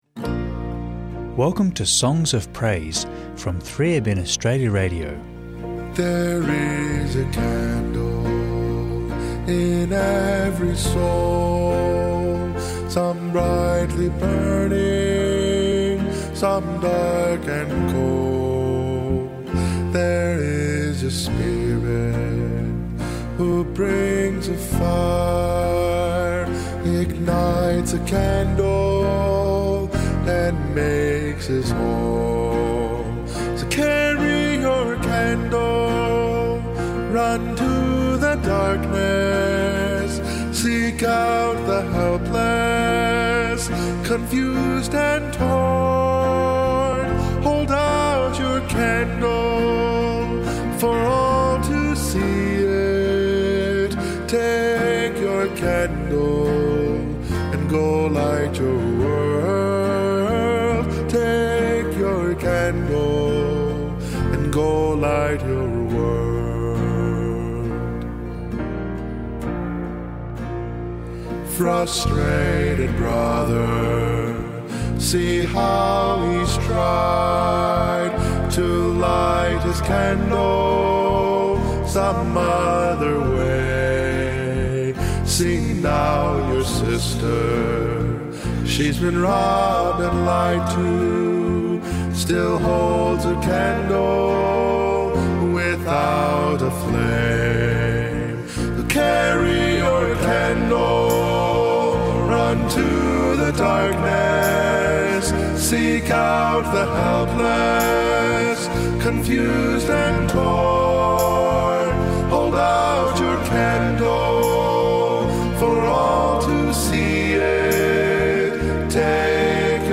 Enjoy musical reflection to encourage, uplift and draw you into a closer relationship with our loving Saviour, Jesus Christ. Featuring the following songs: Also featuring a short 3ABN Australia Radio Book Reading.